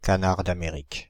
Ääntäminen
Ääntäminen France (Île-de-France): IPA: /ka.naʁ d‿a.me.ʁik/ Haettu sana löytyi näillä lähdekielillä: ranska Käännöksiä ei löytynyt valitulle kohdekielelle.